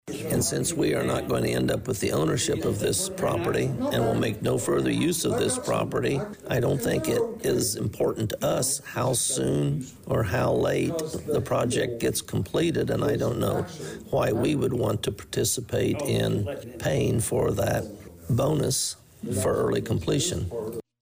During their Thursday evening (Aug 22nd) meeting, the Executive and Legislation Committee unanimously tabled the proposed agreement; hoping to get some questions answered, bring it up in committee again next week, and then perhaps still have it on the agenda for the next full County Board meeting on September 10th.